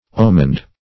Omened \O"mened\, a.